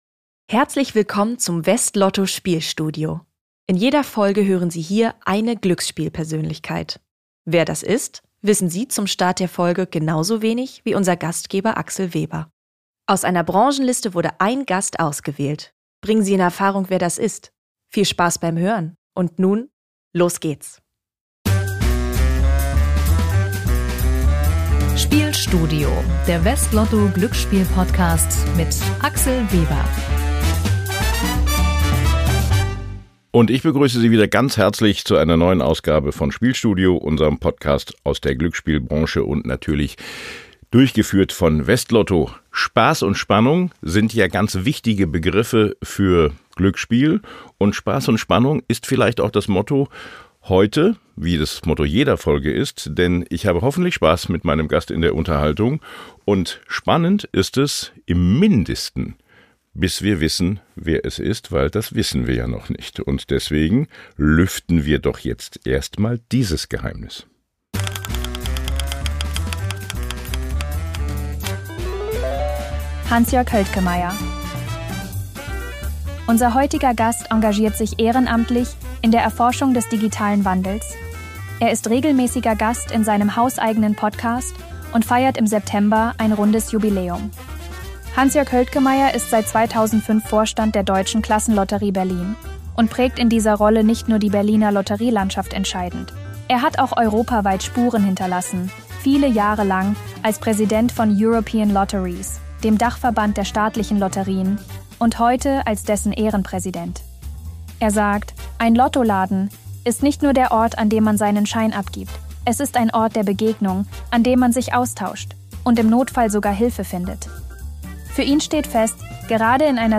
Hinweis: Diese Folge enthält einen Zuspieler, der von einer KI-Stimme gesprochen wird.